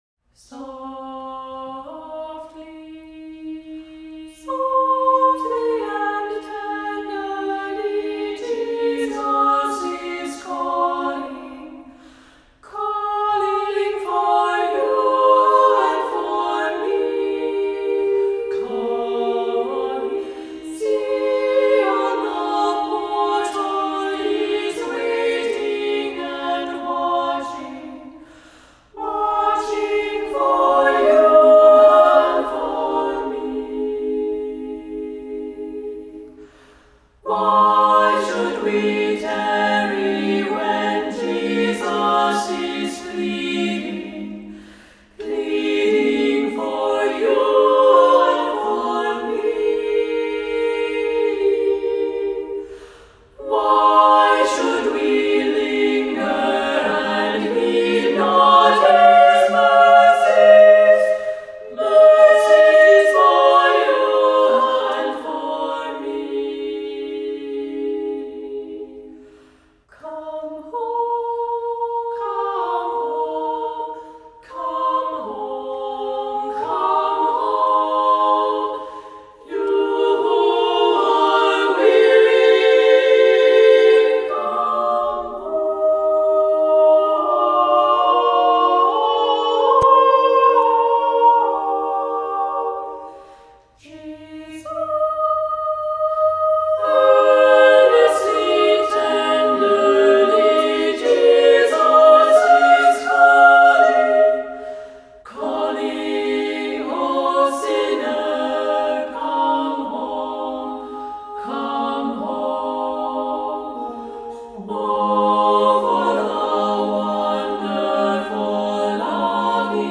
Voicing: Solo; SSAA